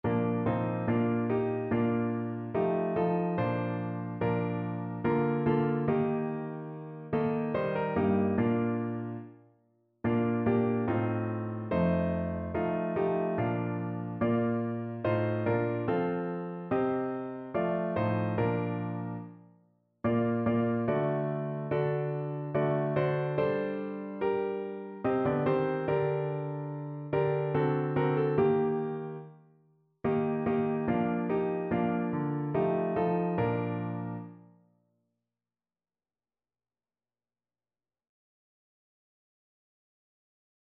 Notensatz 1 (4 Stimmen gemischt)
• gemischter Chor [MP3] 636 KB Download